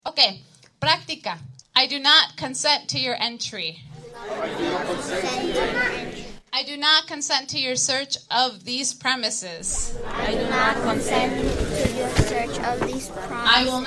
[le hai sentite le voci dei bambini?]